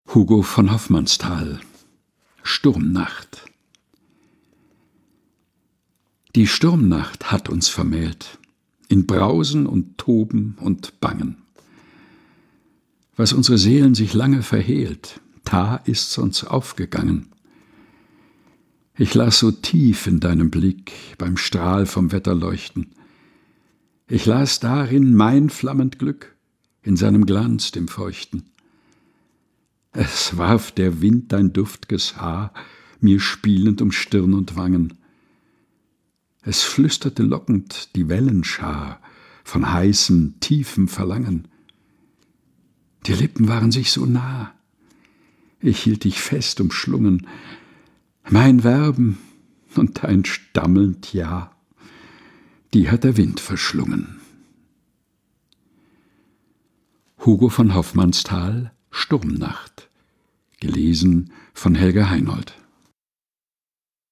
Texte zum Mutmachen und Nachdenken - vorgelesen von